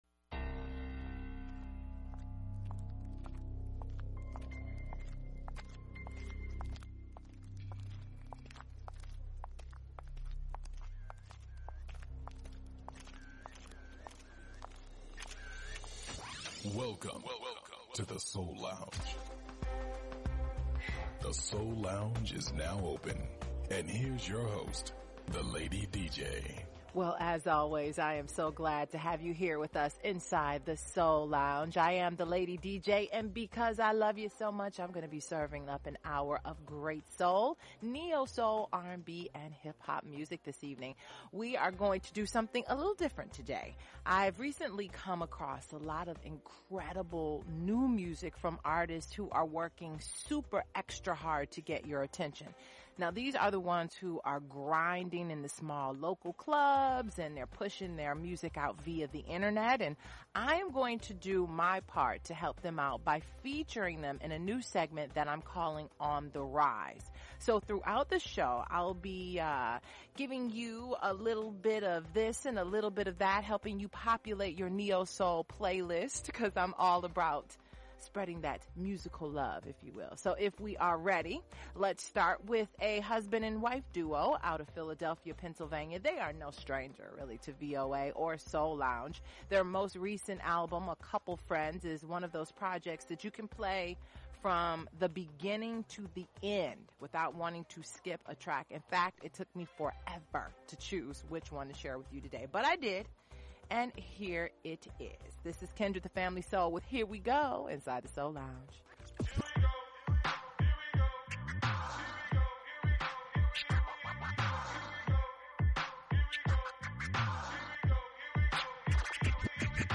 Neo-Soul
conscious Hip-Hop
Classic Soul